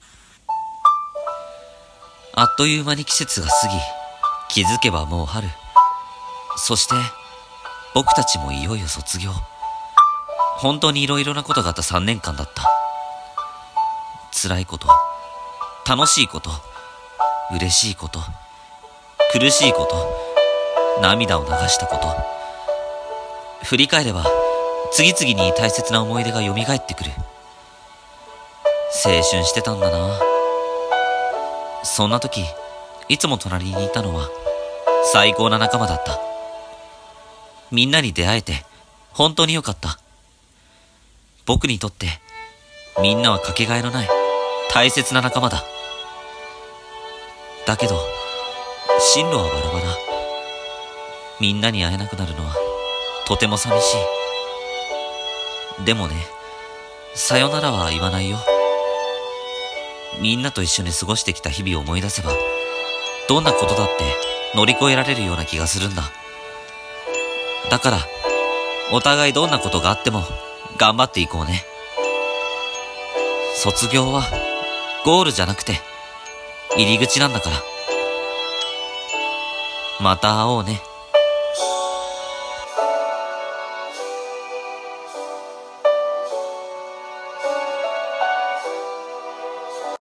【1人声劇朗読台本】